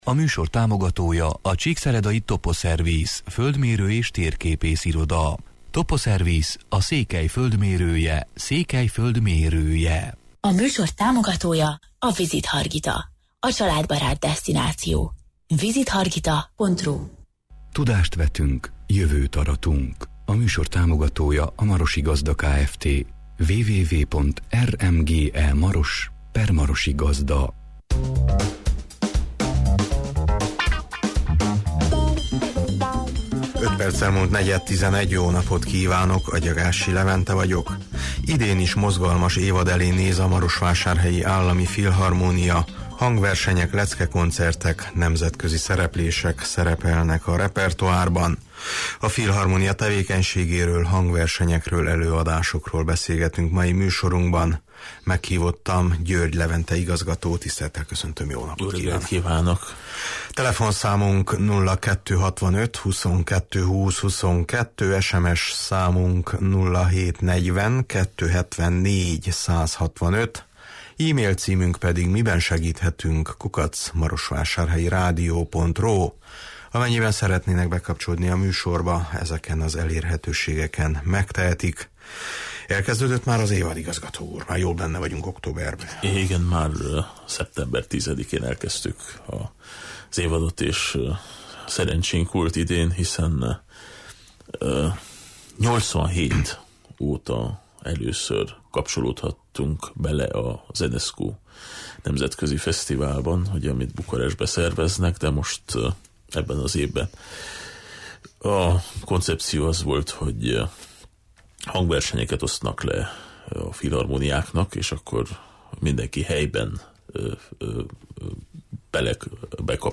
A filharmónia tevékenységéről, hangversenyekről, előadásokról beszélgetünk mai műsorunkban.